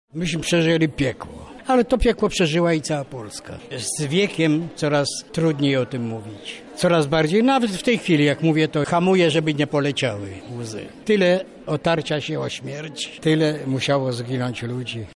Częścią projektu pod tym tytułem było dzisiejsze spotkanie z osobami, które brały udział w Powstaniu Warszawskim. Aula Centrum transferu Wiedzy KUL wypełniła się młodymi ludźmi, których łączy zainteresowanie historią Polski.